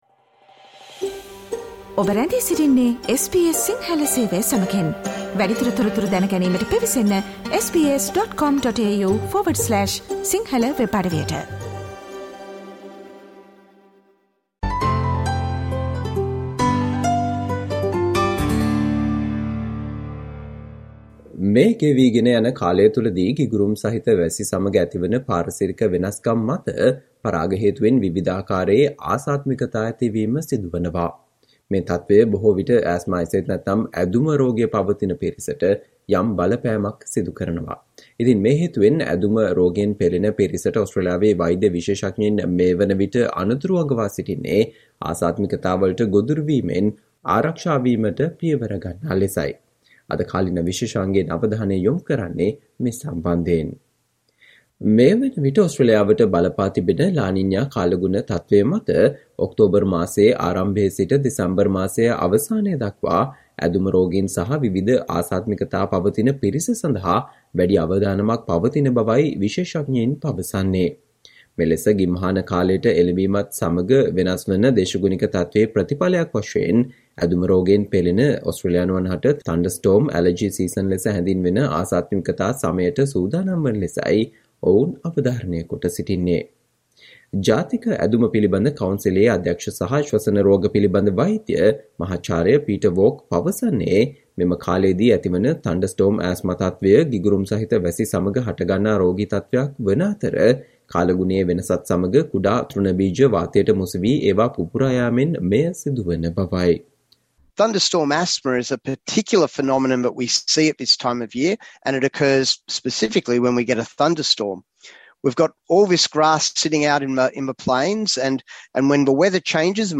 Today - 26 September, SBS Sinhala Radio current Affair Feature on Experts are warning Australians who suffer from asthma to prepare for a thunderstorm allergy season